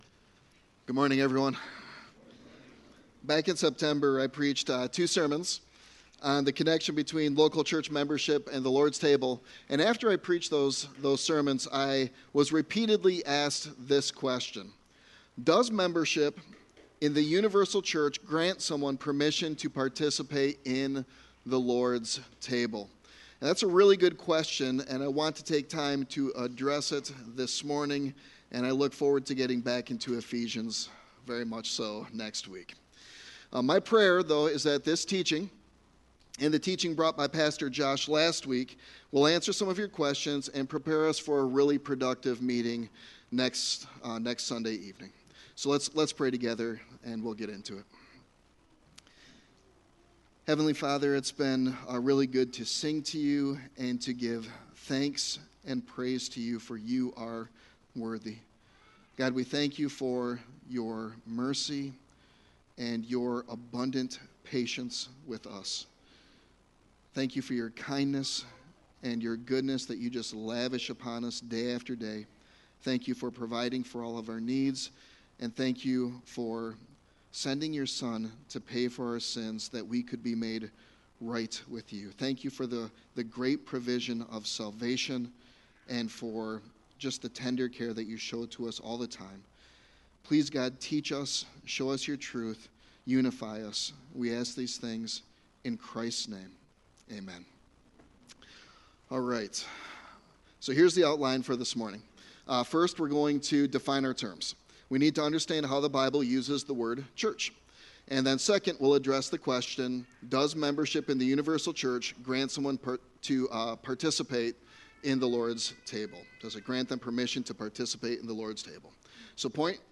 Sermon Text: Various Texts